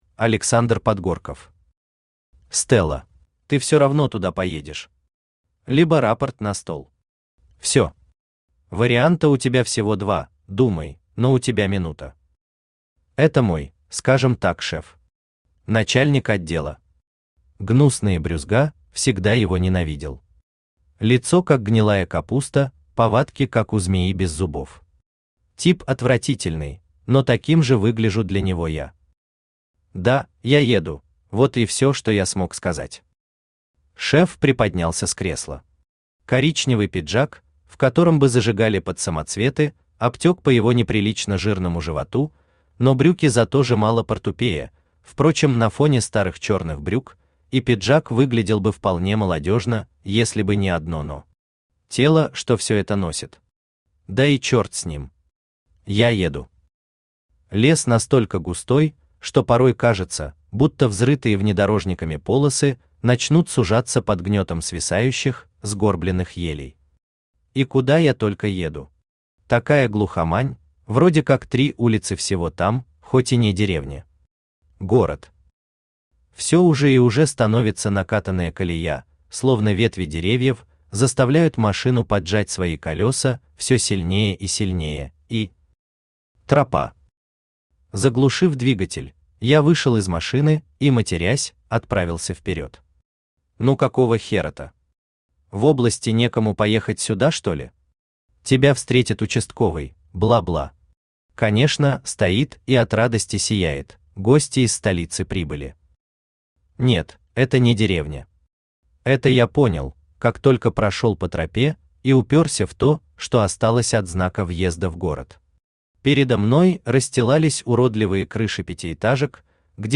Аудиокнига Стелла | Библиотека аудиокниг
Aудиокнига Стелла Автор Александр Подгорков Читает аудиокнигу Авточтец ЛитРес.